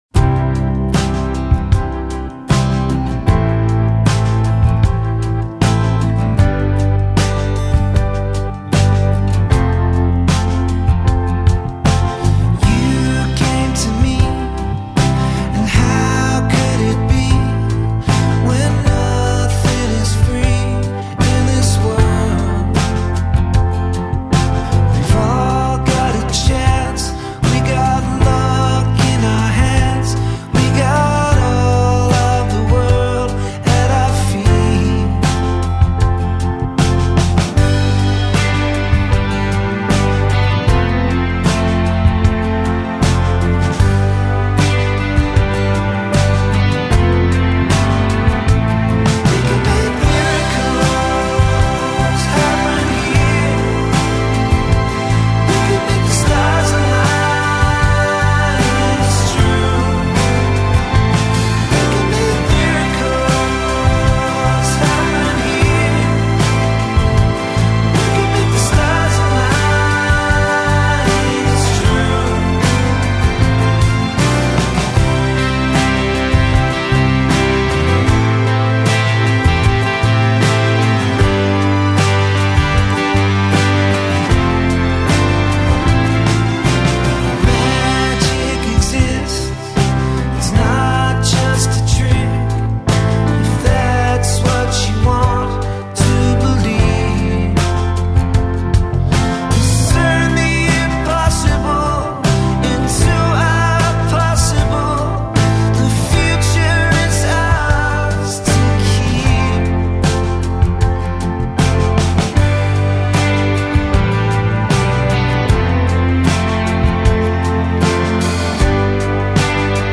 dizi müziği, mutlu huzurlu rahatlatıcı şarkı.